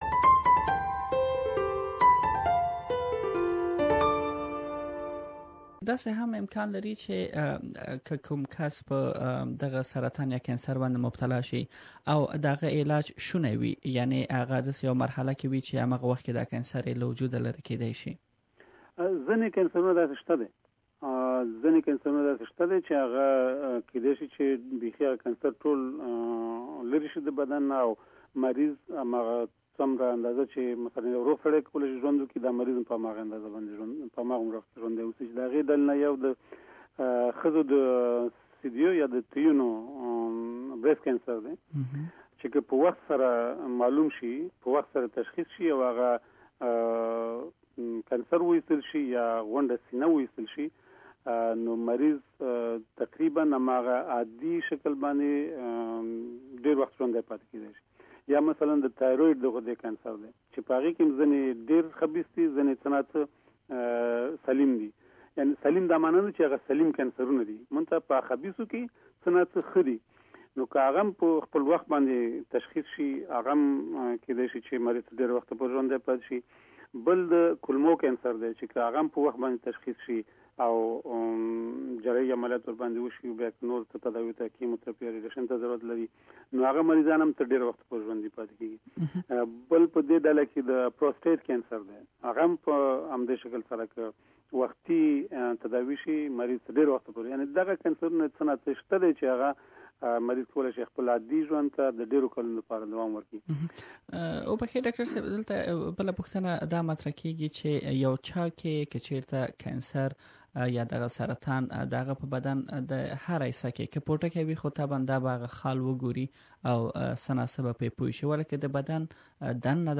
He has some interesing information to share, Please listen to the 2nd part of this interview.